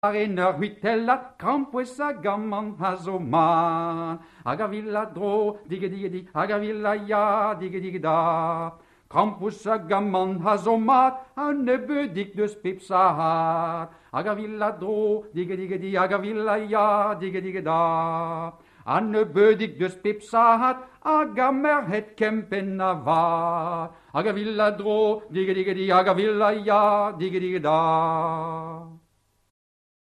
Les dictons et contes parlent de larcins professionnels, mais c’est le meunier « voleur de filles » que peint la chanson traditionnelle. Dans Mélinérez Pontaro (Cornouailles), le meunier est accusé d’avoir enlevé, Fanchon, la belle du tailleur bossu.